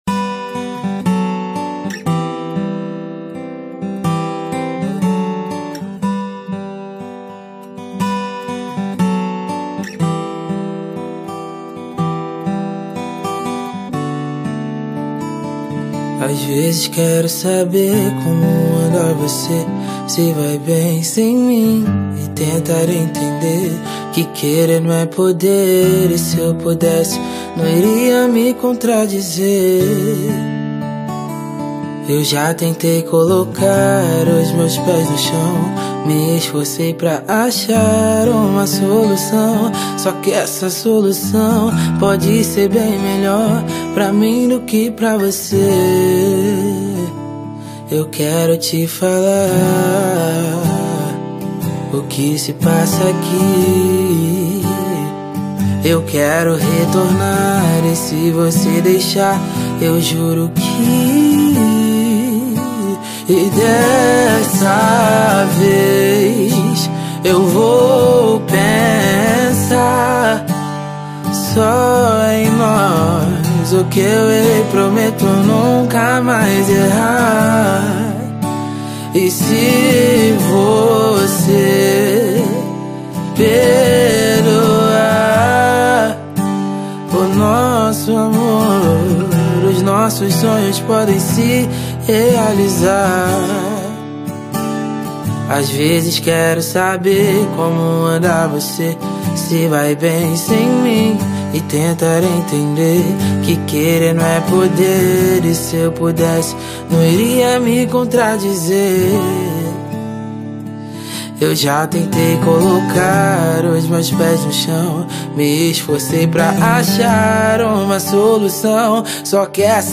ac?stico